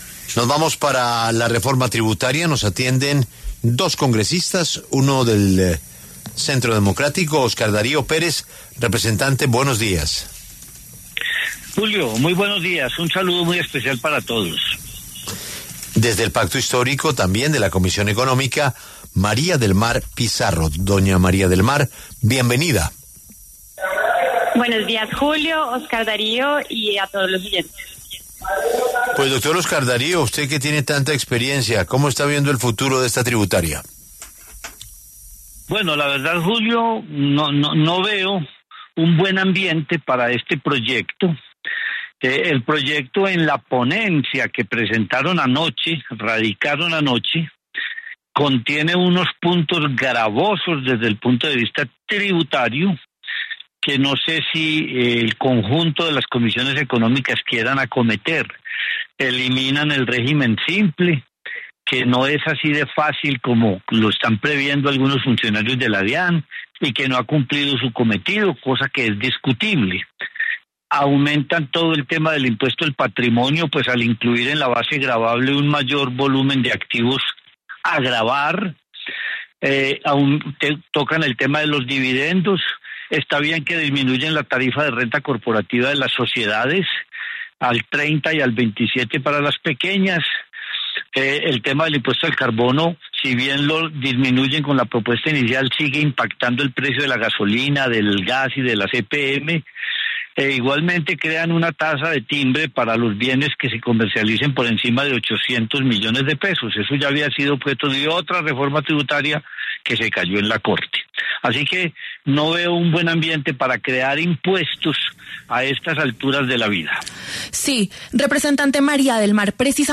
Los representantes Óscar Darío Pérez, del Centro Democrático, y María del Mar Pizarro, del Pacto Histórico, debatieron en los micrófonos de La W.